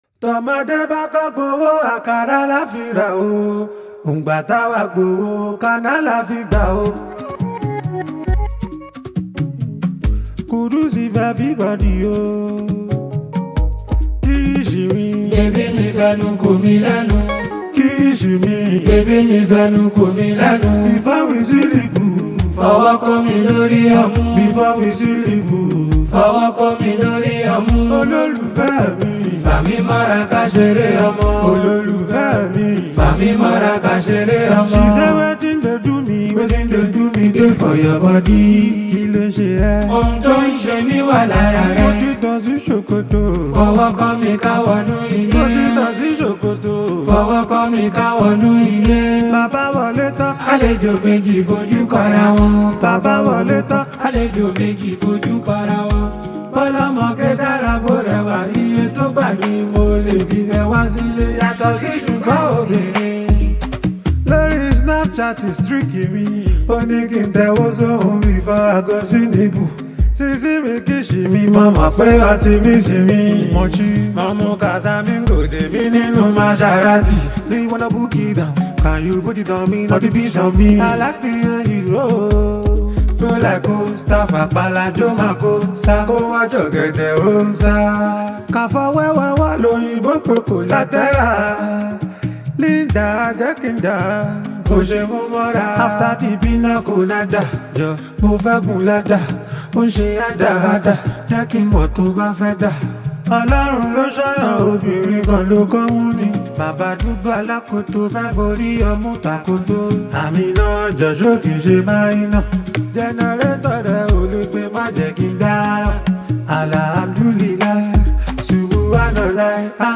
Nigerian street-hop artist